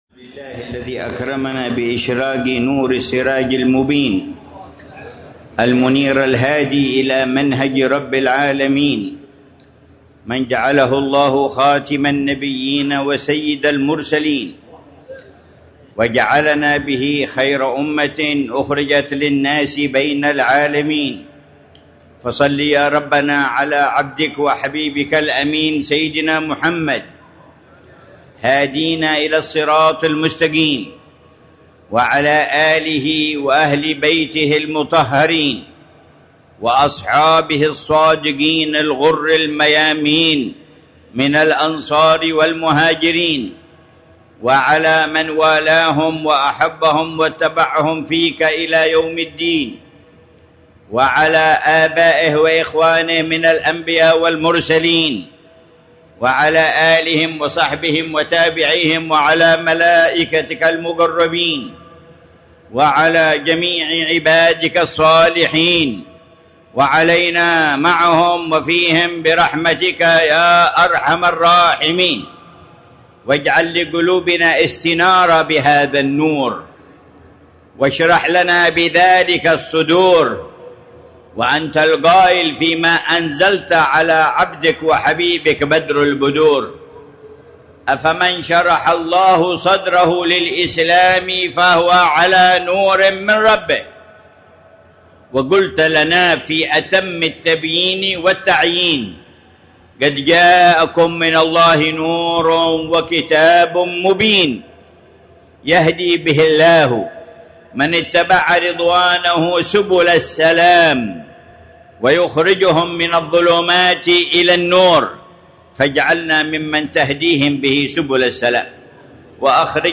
محاضرة العلامة الحبيب عمر بن محمد بن حفيظ في مسجد حسن في حارة خليف السحيل، تريم، ليلة الخميس 3 ربيع الثاني 1447هـ بعنوان: